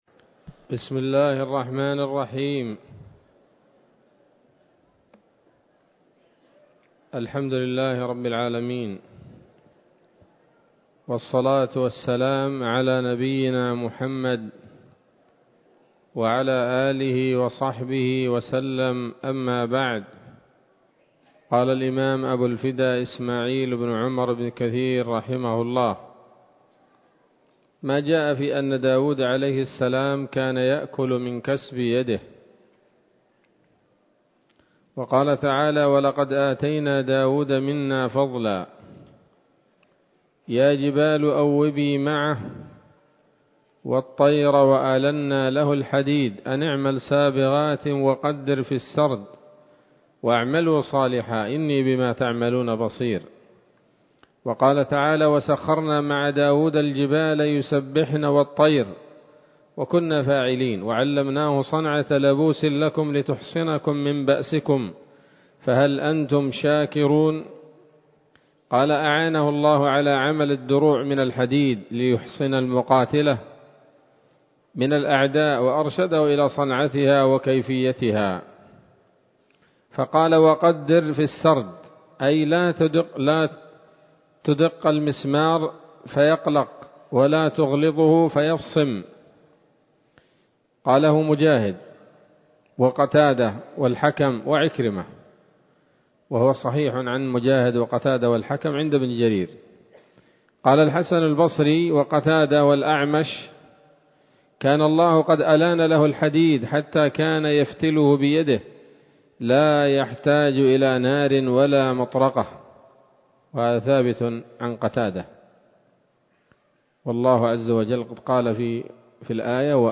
‌‌الدرس الثامن عشر بعد المائة من قصص الأنبياء لابن كثير رحمه الله تعالى